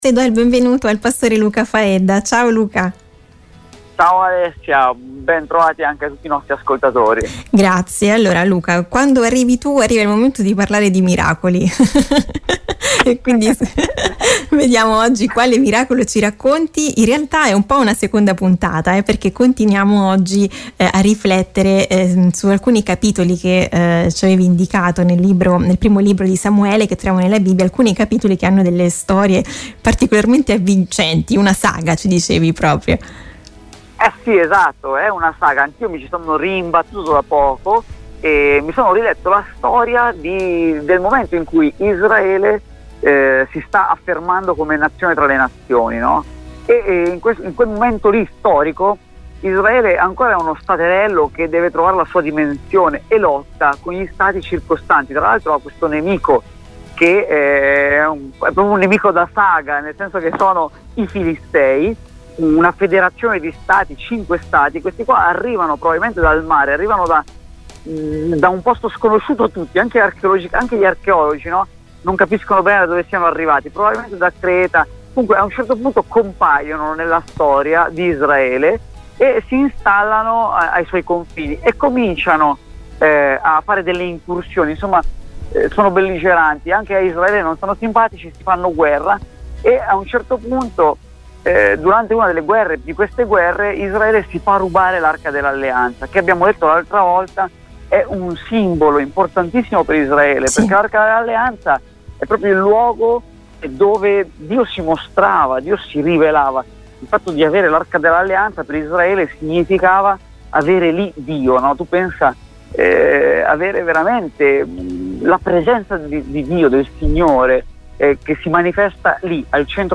Qual è il messaggio di questa storia per noi oggi? Intervista